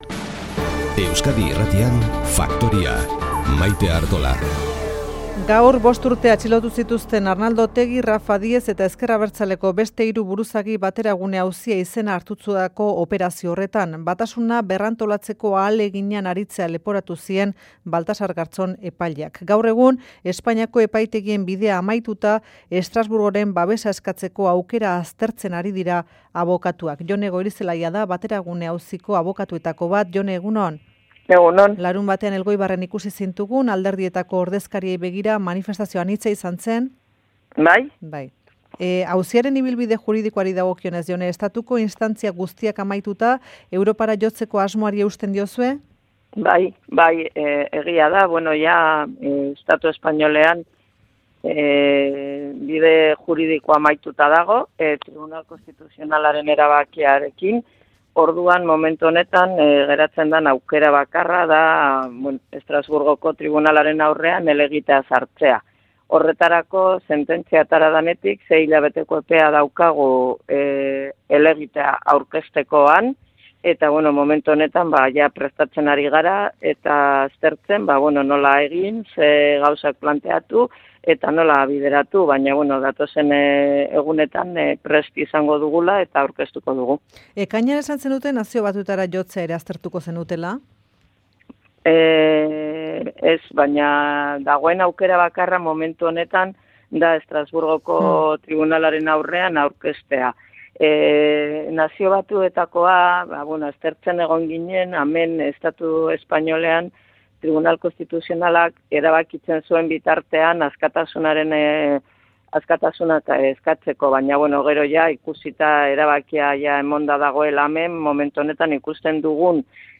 Euskadi Irratia. (Entrevista en euskera) Whatsapp Whatsapp twitt telegram Enviar Copiar enlace nahieran